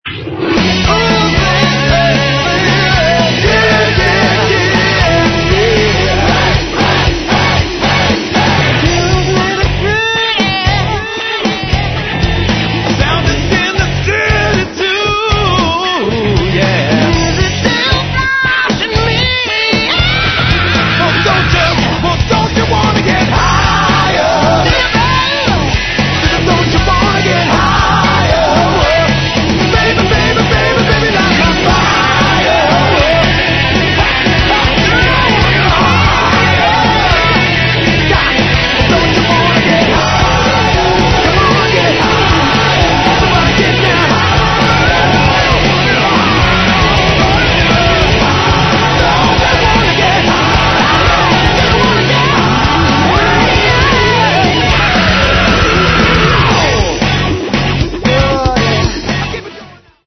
Рок
vocals
drums
lead guitars
keyboards
bass & guitars